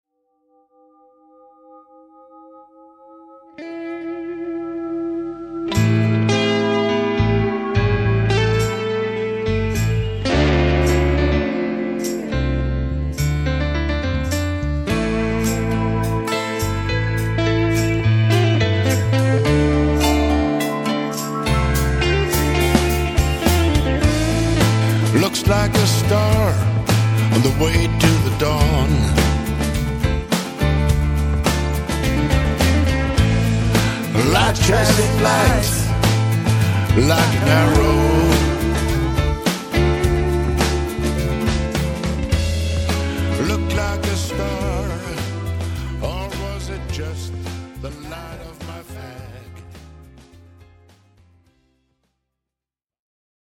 and recorded at home